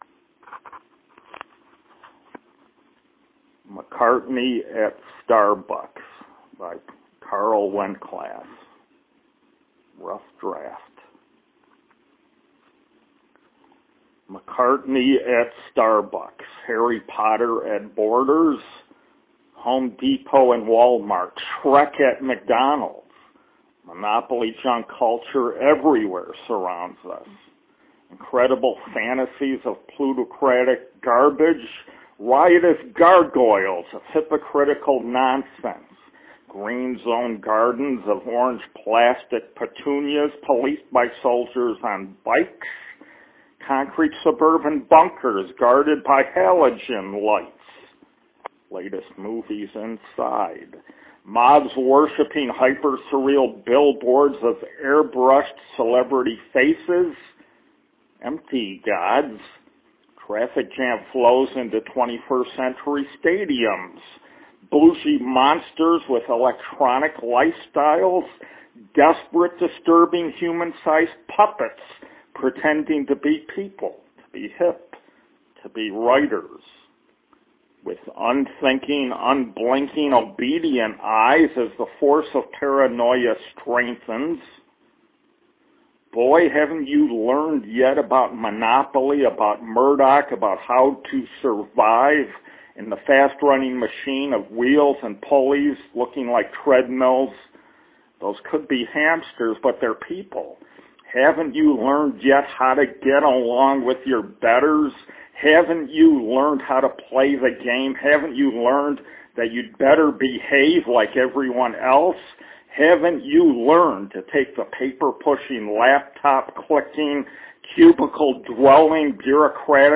A seven-minute rumination on fame, celebrity, the sterility of contemporary society, the necessity of authenticity and the costs of success. The reading starts out slow but the words are what count.